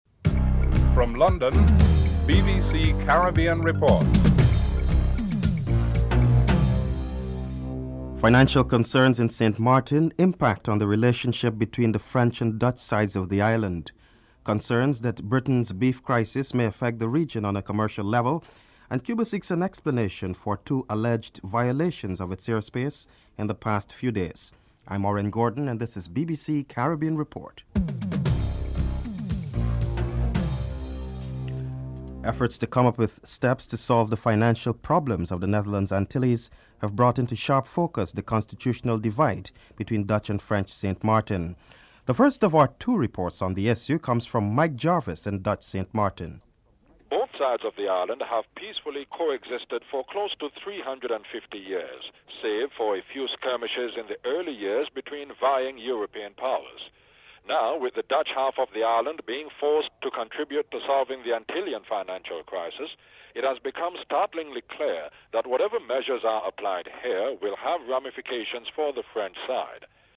1. Headlines (00:00-00:31)
4. Integration of smaller economies in the proposed Free Trade Areas of the Americas will be critical for the region. Jamaica's Ambassador to Washington Dr Richard Bernal is interviewed (09:07-11:38)